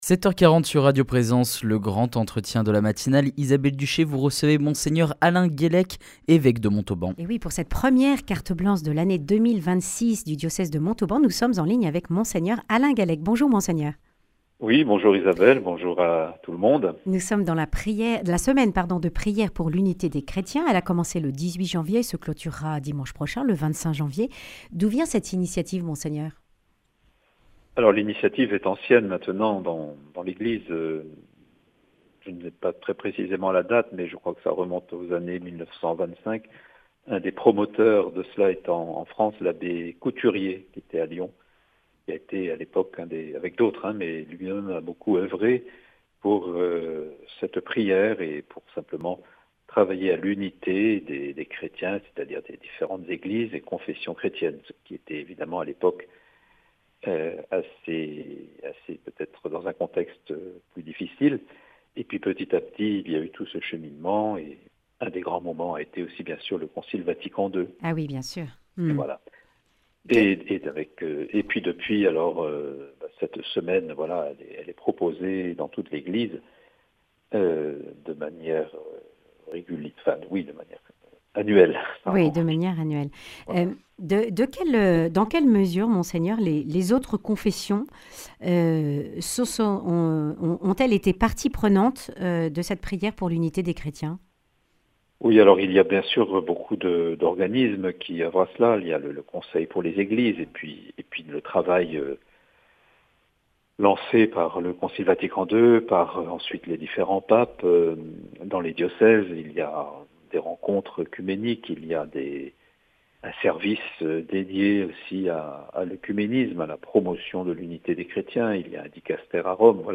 Le grand entretien